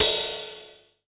Brush Ride1.wav